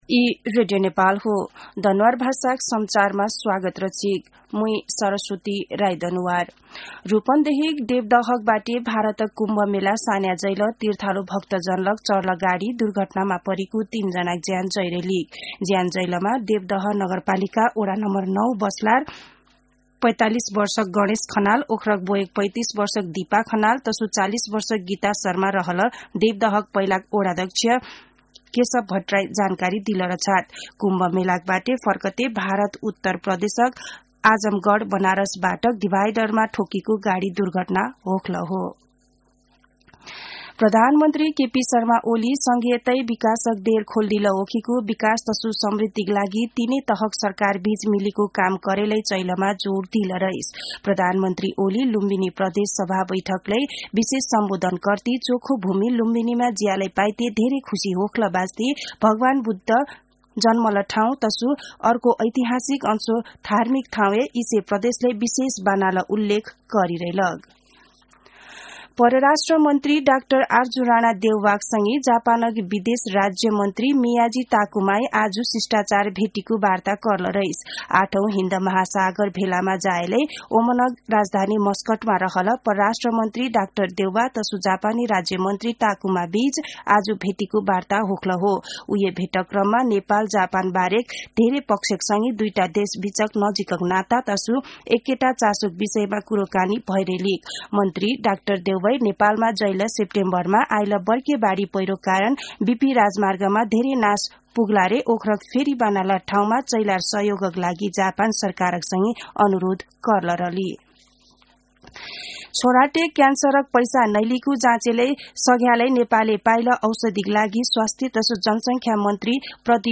दनुवार भाषामा समाचार : ६ फागुन , २०८१
danuwar-news-2.mp3